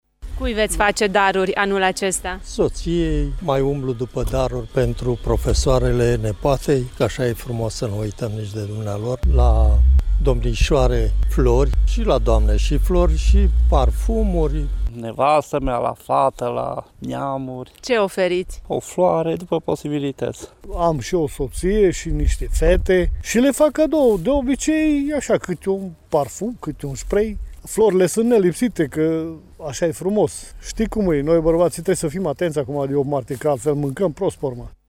Florile continuă să fie cadoul ce mai des oferit cu ocazia zilei de 8 martie, indiferent de vârstă, spun bărbații mureșeni: